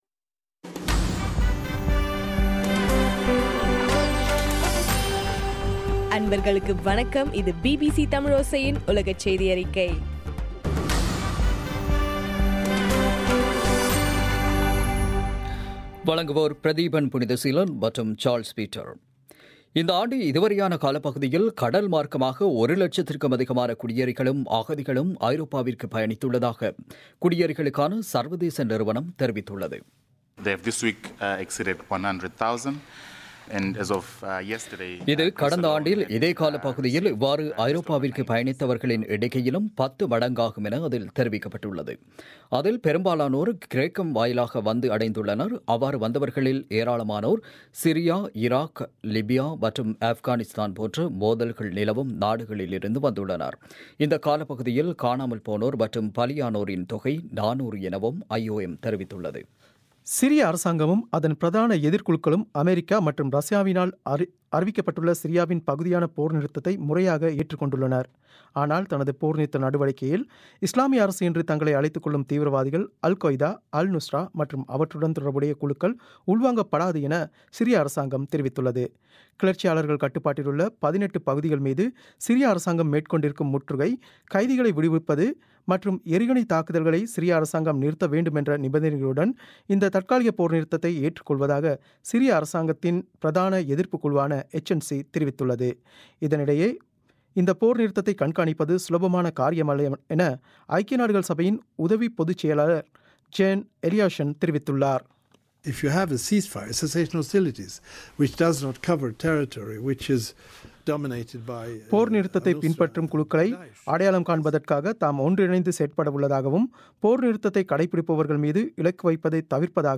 பிப்ரவரி 23 பிபிசியின் உலகச் செய்திகள்